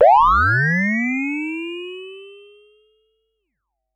Index of /musicradar/essential-drumkit-samples/Vermona DRM1 Kit
Vermona Fx 03.wav